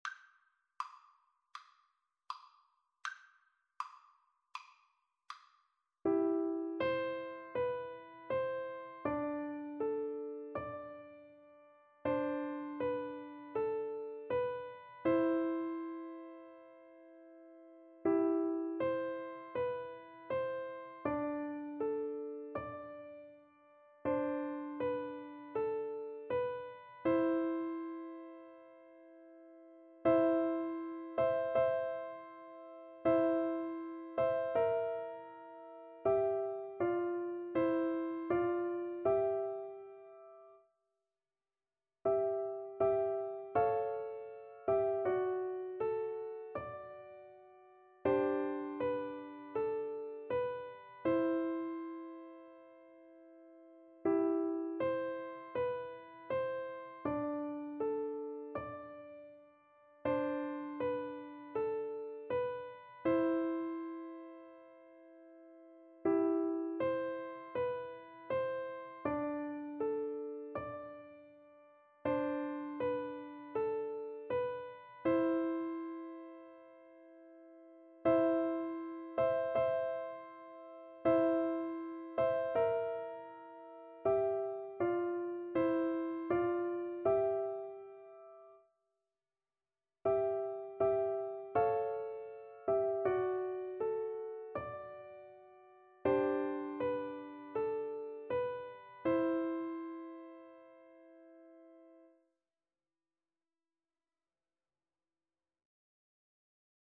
C major (Sounding Pitch) (View more C major Music for Piano Duet )
Andante = c. 80
Piano Duet  (View more Easy Piano Duet Music)